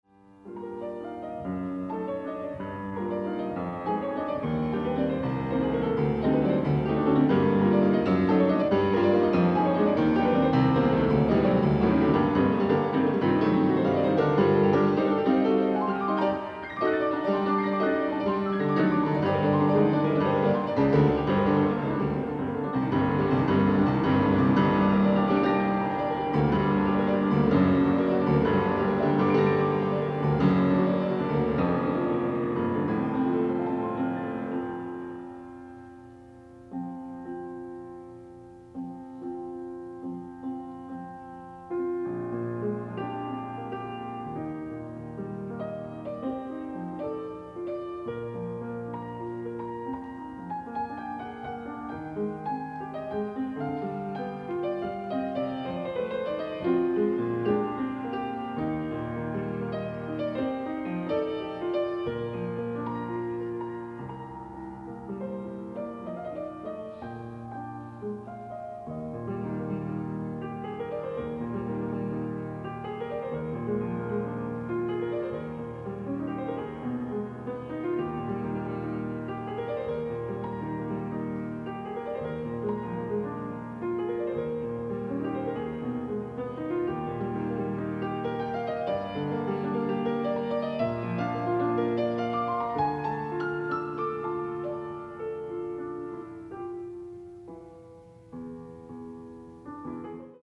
Additional Date(s)Recorded September 14, 1977 in the Ed Landreth Hall, Texas Christian University, Fort Worth, Texas
Suites (Piano)
Sonatas (Piano)
Short audio samples from performance